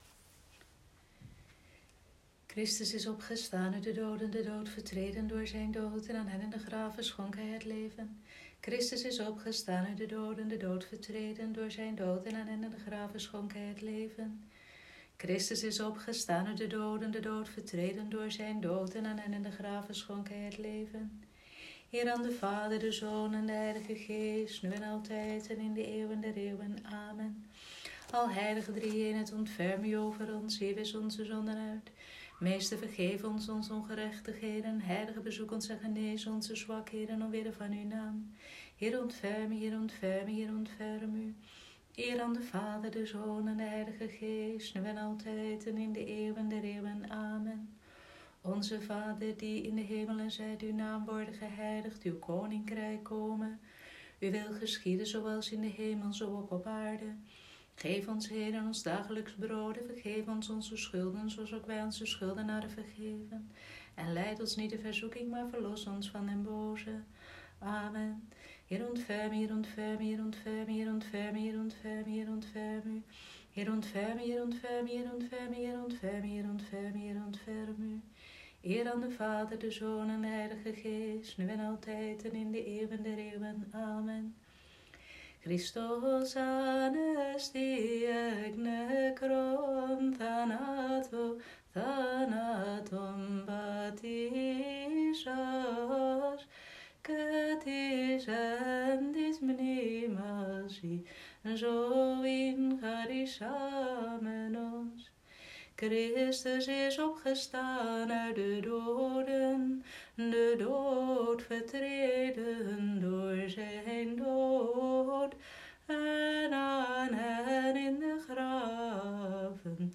Vespers dinsdagavond, 28 april 2020
Vespers-dinsdagavond-28-april.m4a